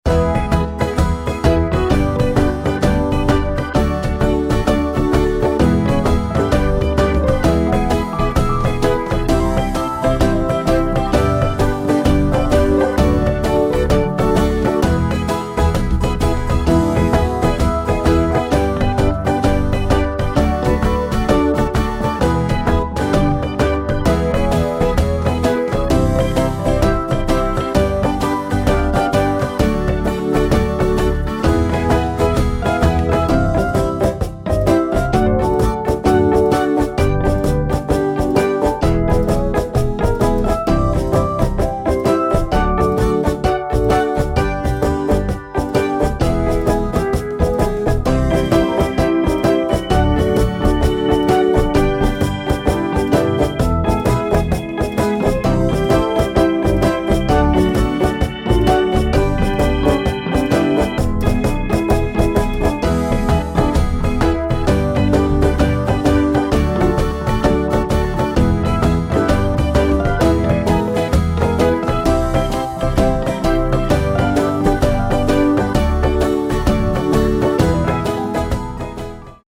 midi-demo 2